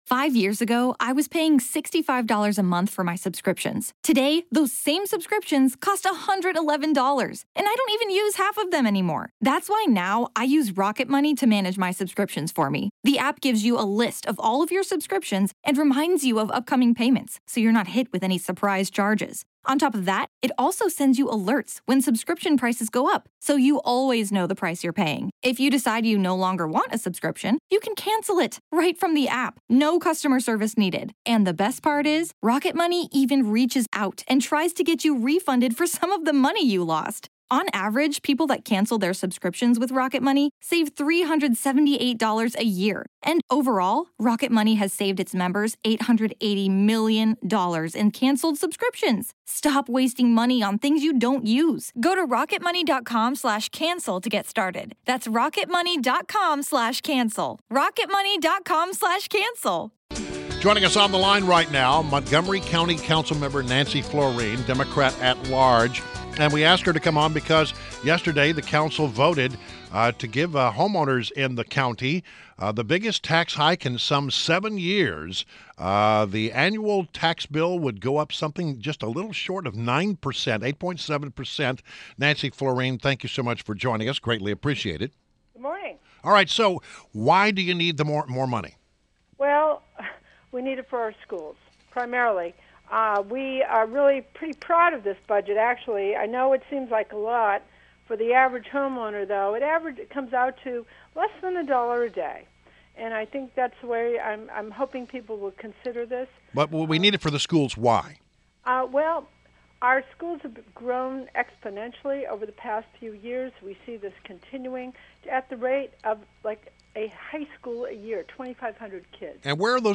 WMAL Interview - Nancy Floreen 05.20.16
INTERVIEW – Montgomery County Councilmember NANCY FLOREEN – Montgomery County Council President (D-At Large)